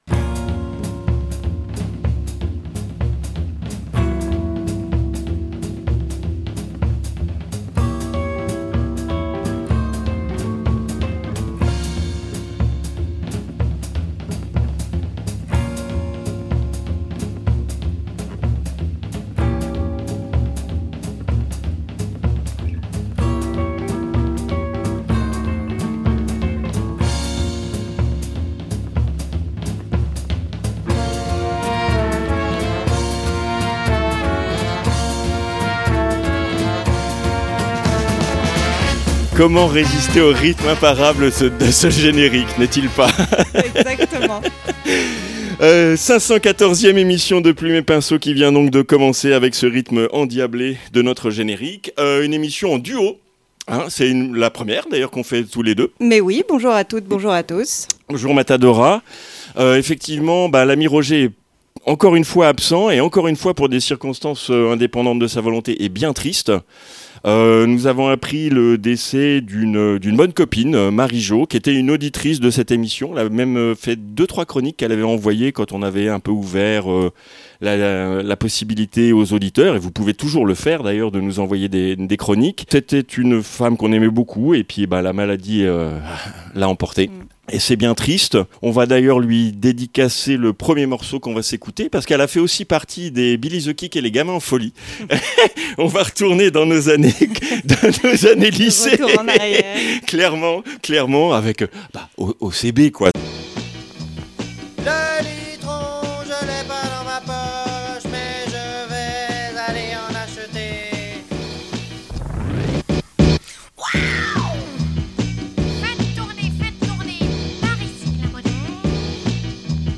I - INTERVIEW